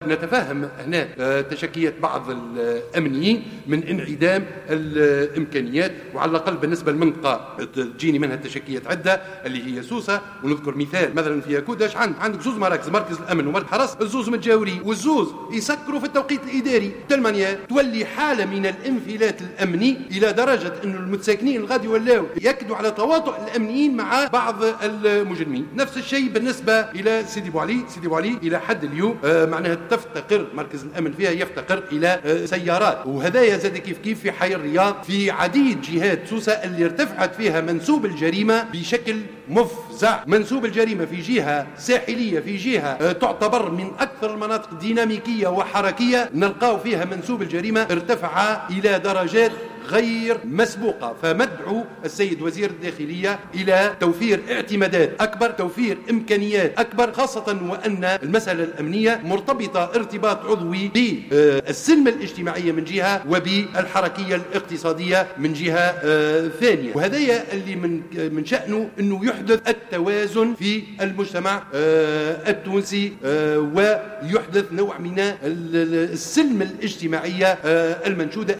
أكد اليوم الإثنين ،النائب بالبرلمان عن التيار الديمقراطي رضا الزغمي ، في مداخلة له بالجلسة العامة بالبرلمان إرتفاع منسوب الجريمة بشكل مفزع بعدد من الجهات بولاية سوسة على غرار حي الرياض وسيدي بوعلي .